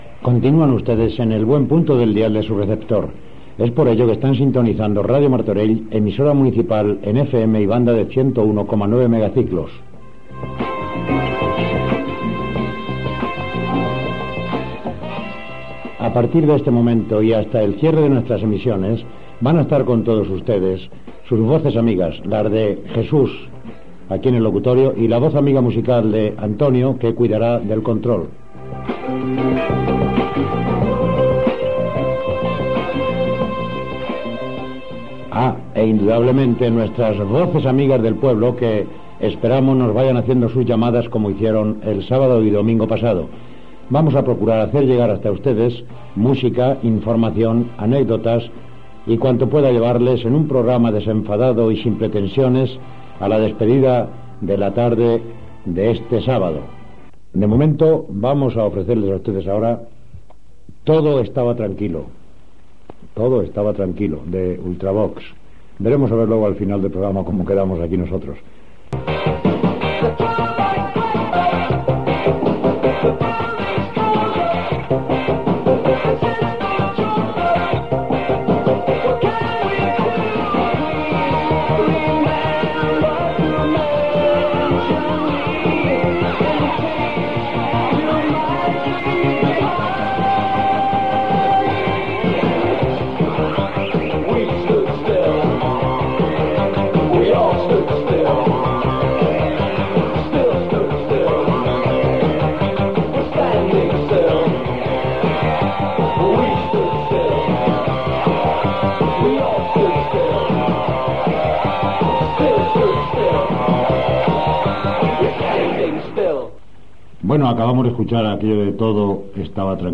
Presentació d'un tema musical Gènere radiofònic Musical